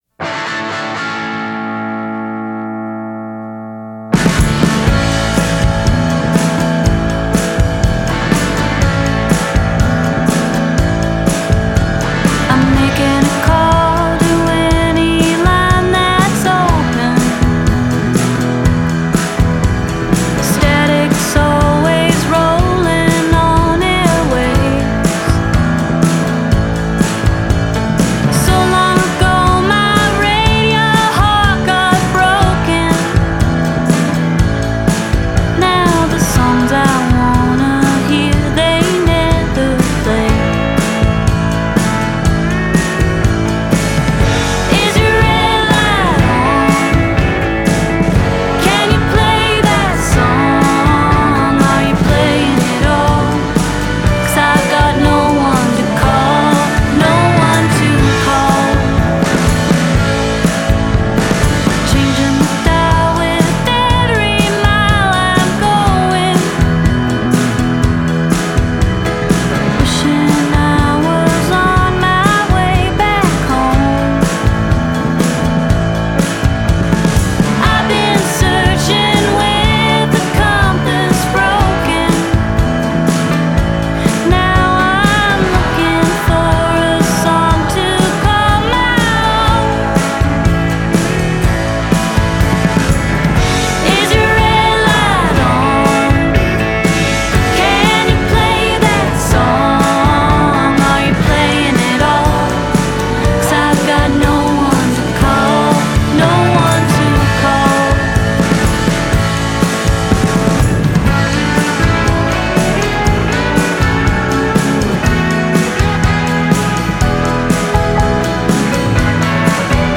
but despite some pedal steel in the arrangements
wall-of-sound arrangement